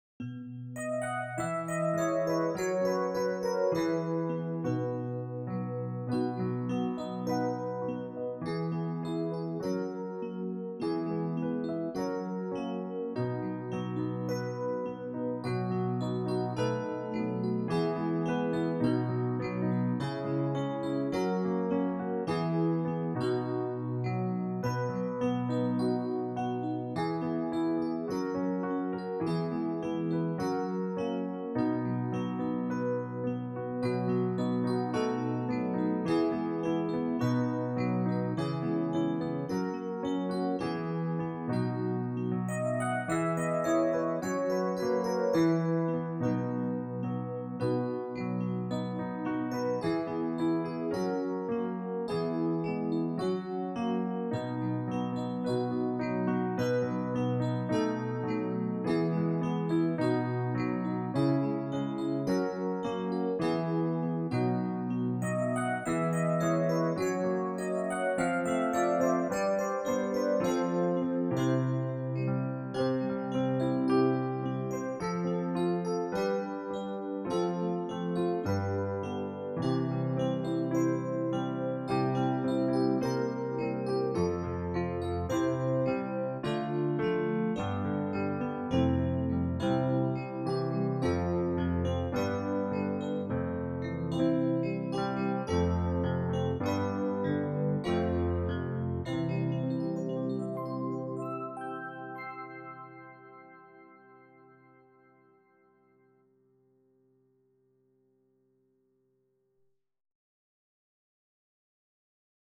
playback com piano